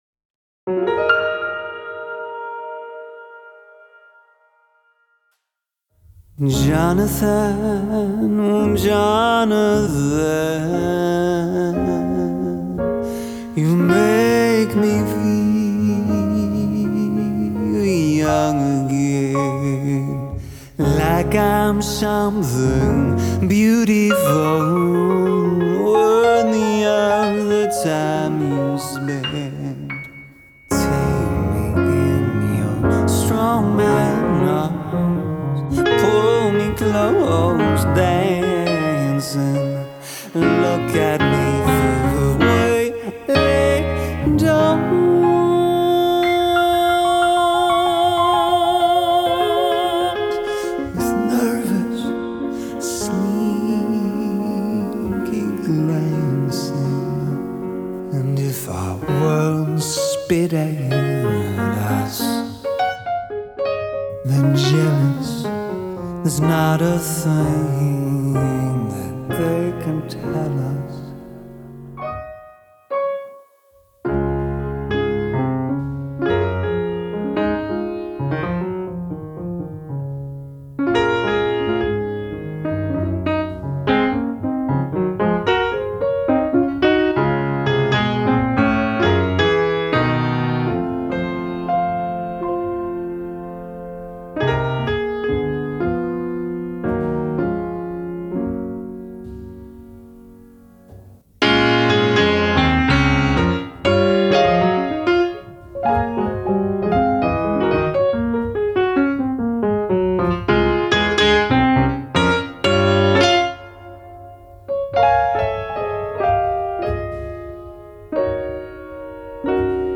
This is a character song.